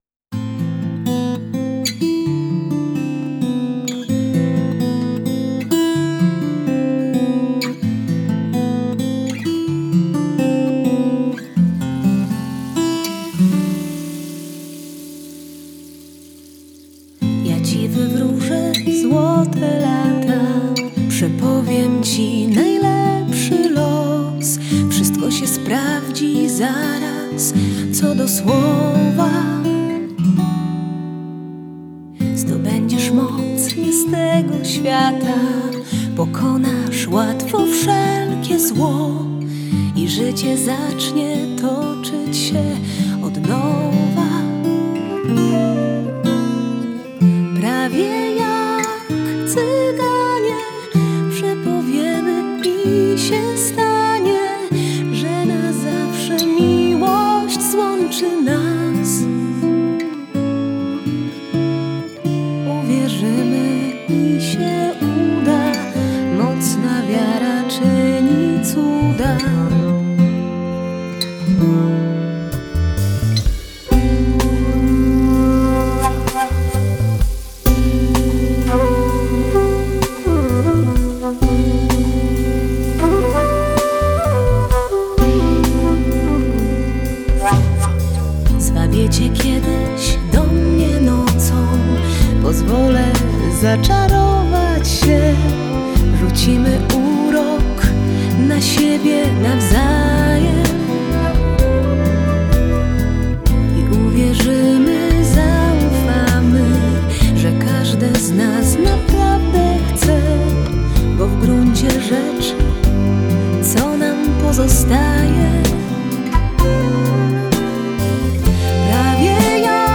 Genre: Folk-Rock